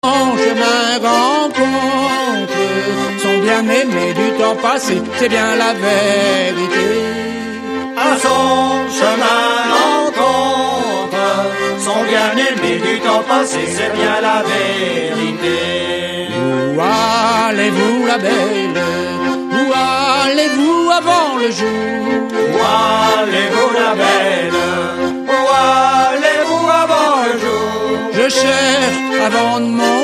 Musique : Traditionnel
Origine : Bretagne
Danse : Ronde à trois pas